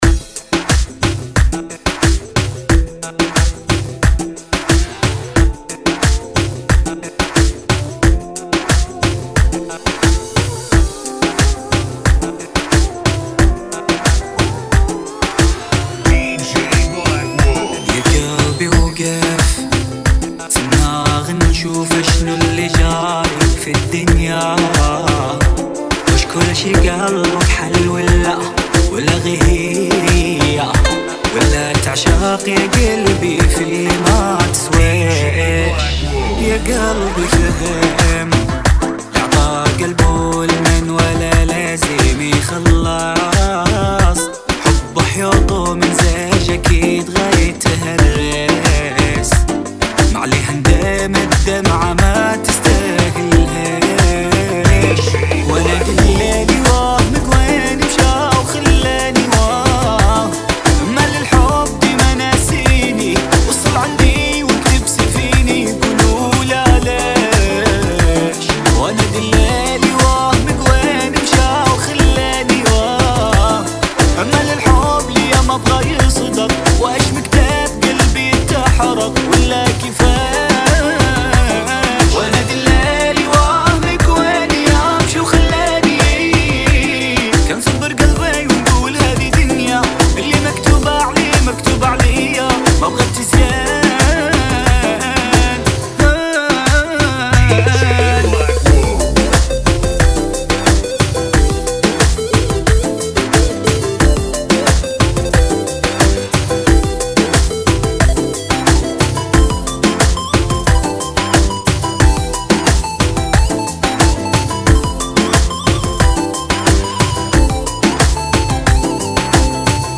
[ 90 Bpm ]